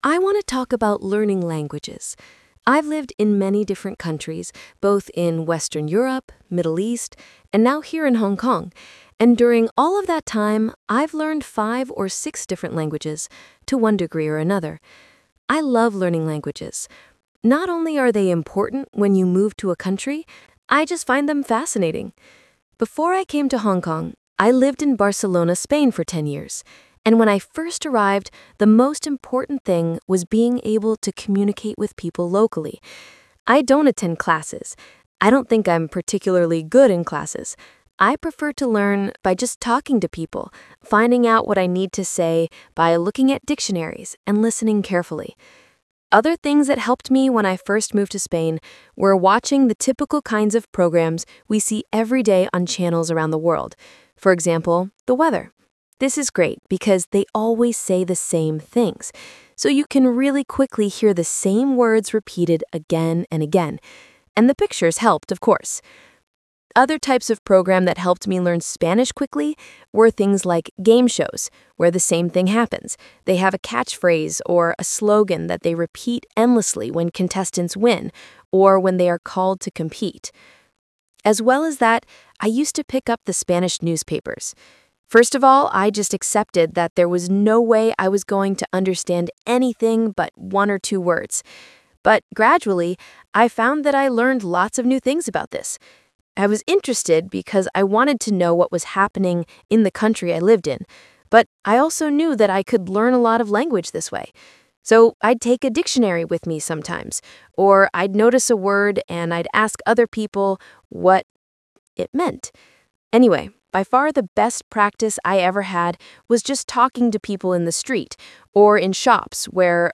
Talk/Lecture 1: You will hear a talk about learning a language.